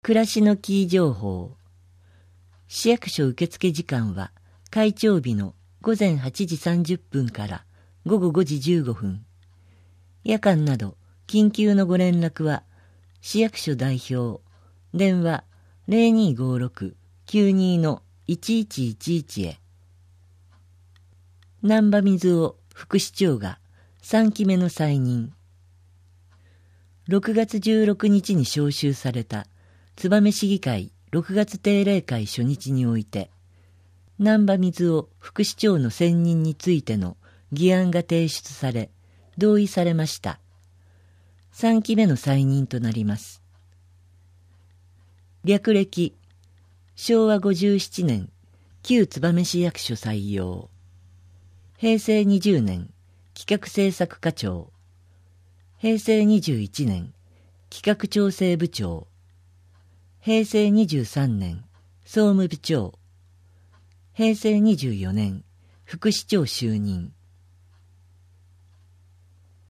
こちらではMP3版の声の広報を、項目ごとに分けて配信しています。